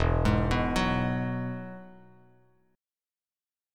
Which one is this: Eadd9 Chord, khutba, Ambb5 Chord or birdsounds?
Eadd9 Chord